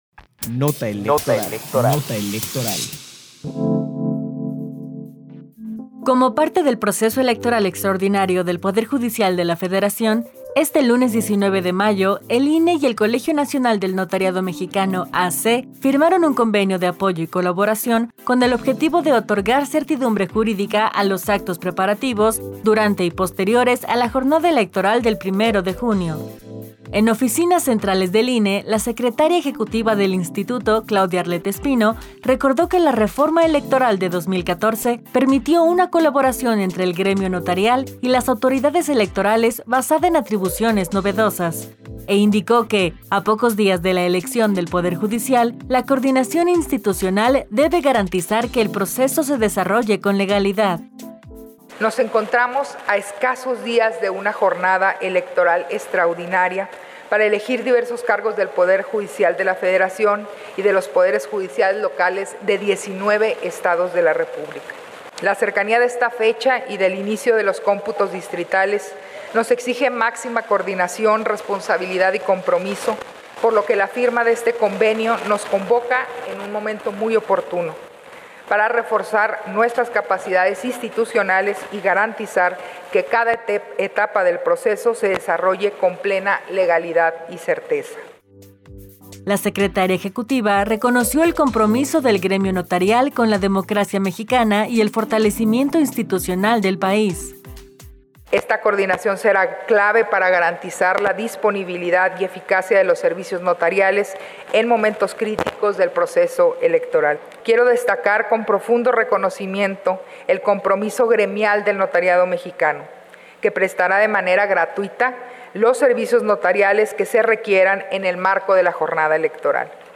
Nota de audio sobre la firma de convenio entre el INE y el Colegio Nacional del Notariado Mexicano, 19 de mayo de 2025